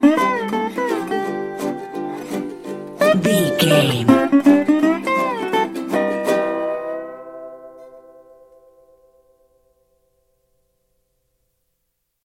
Ionian/Major
acoustic guitar
banjo
percussion
ukulele
slack key guitar